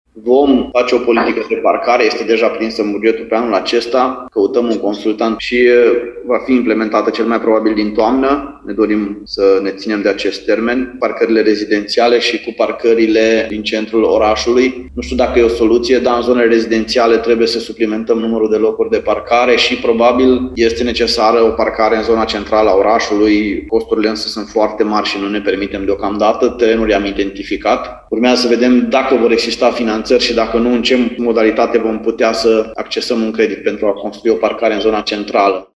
Primarul Lugojului, Claudiu Buciu